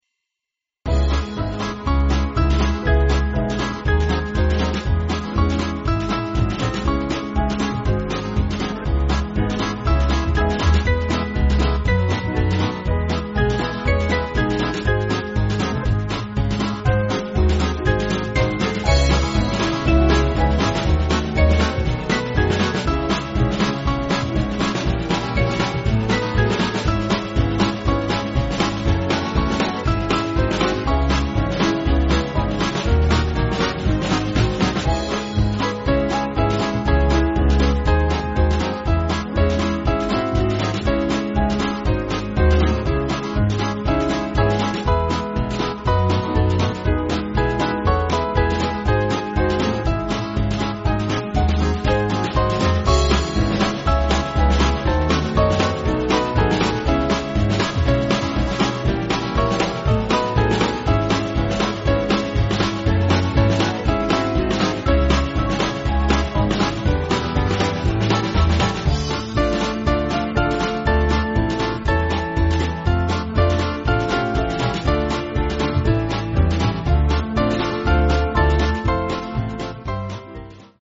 African American spirtual
Small Band
(CM)   3/Dbm-Dm
Lively